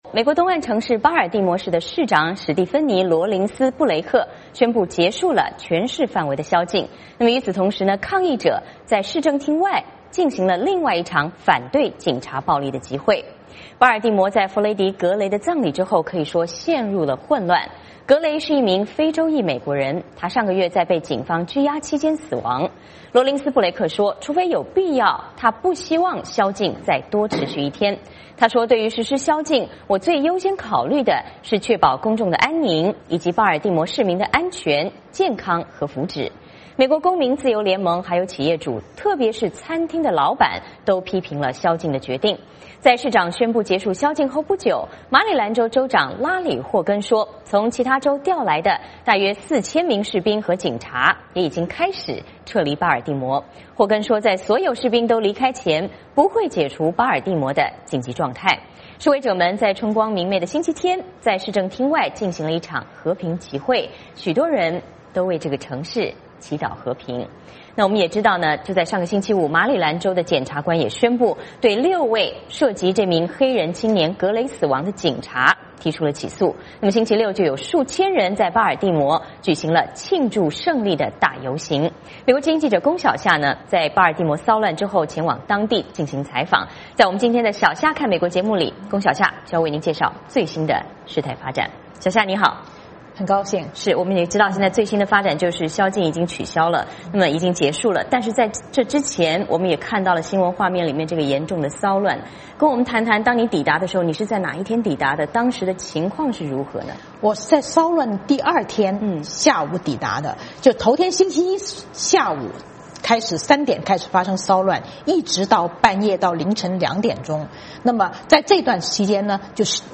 并且采访了周六游行的组织者